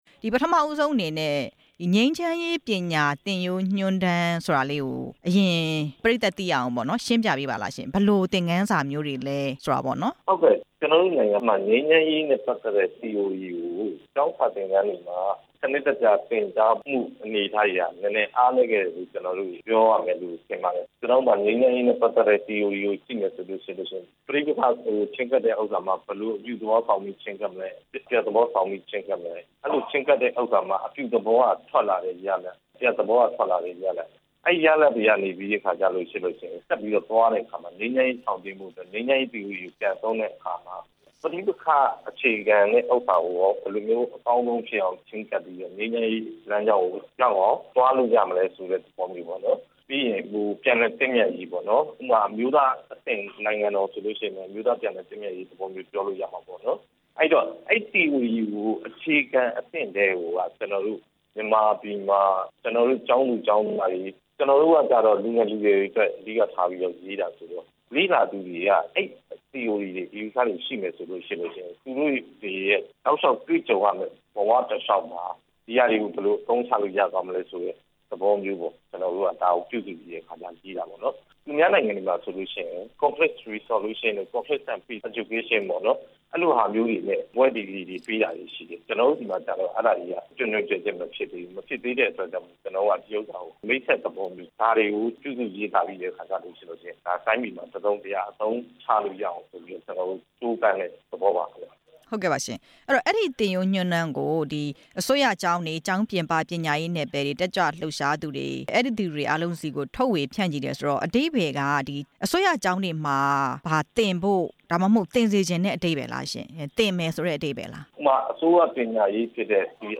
ငြိမ်းချမ်းရေးပညာရေး သင်ရိုးညွှန်းတမ်းစာအုပ် အကြောင်း မေးမြန်းချက်